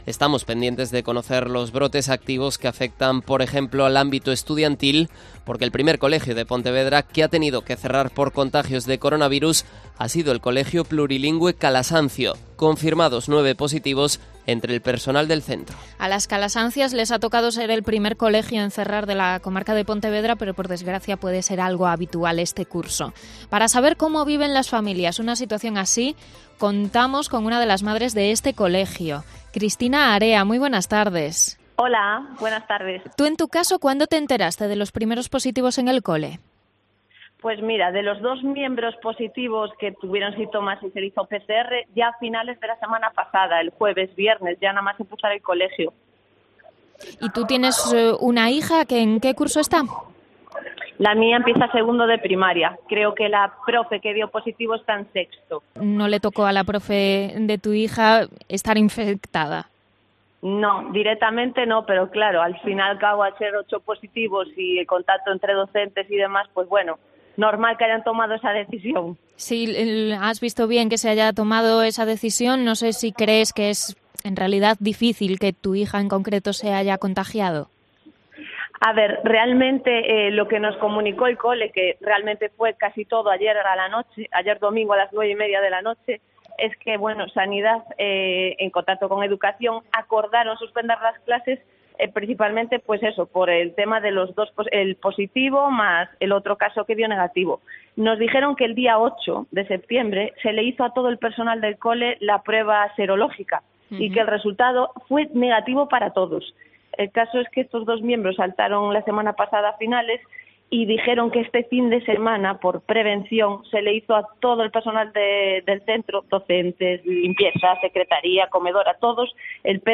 Entrevista a una madre del colegio Calasancio de Pontevedra tras el cierre por un brote de covid-19